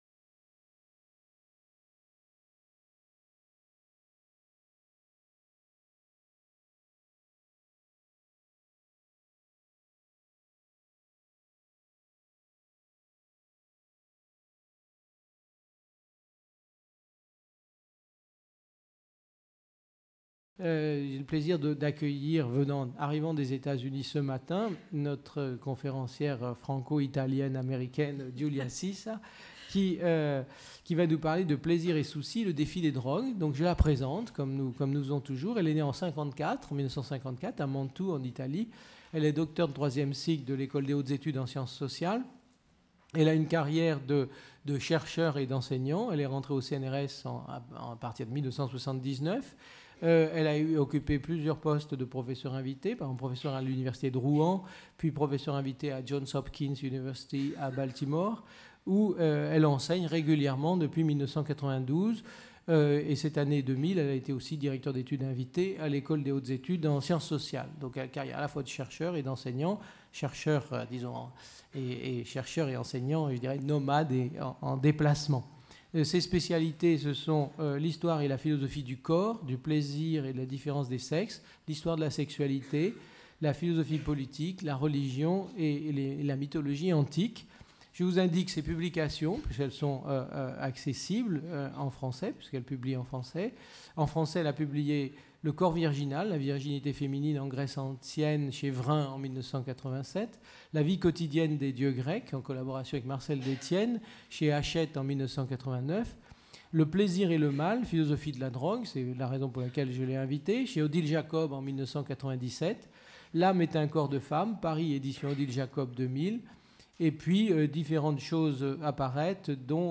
Une conférence